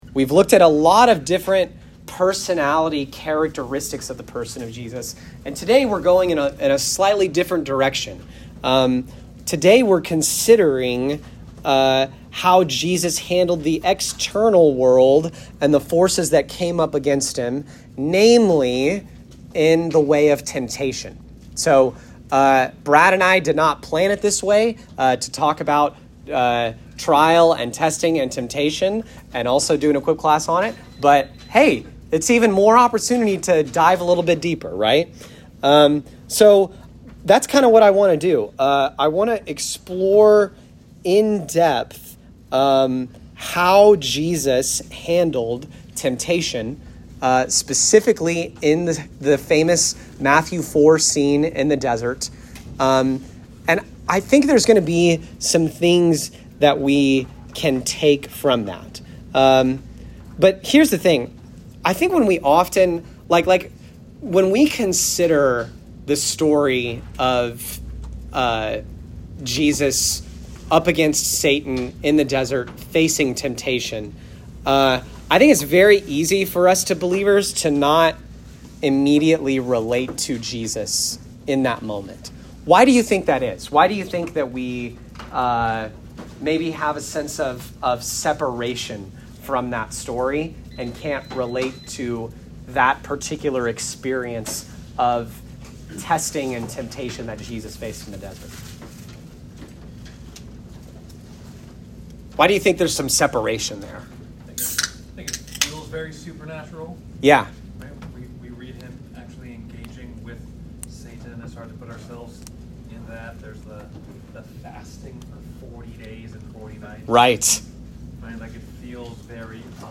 Equip Class: Behold The Man - Lesson 6: Jesus and Temptation